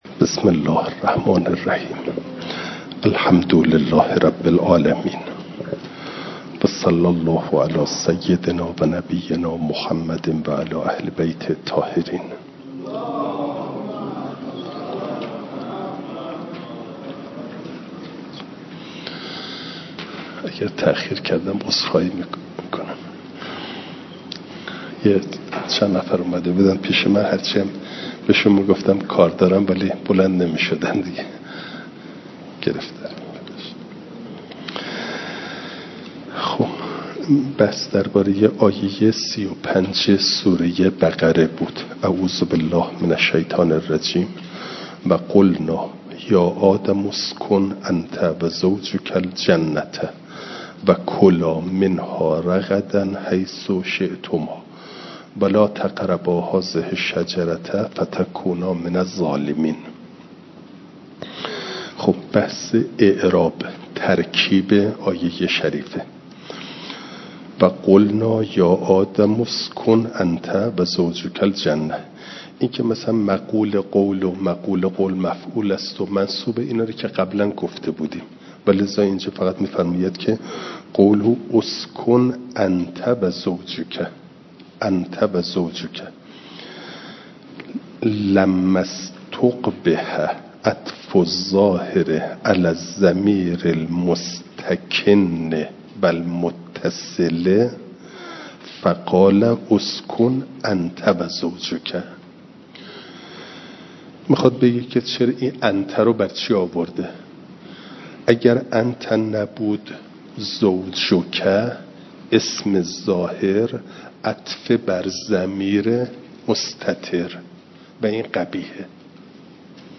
فایل صوتی جلسه چهل و هفتم درس تفسیر مجمع البیان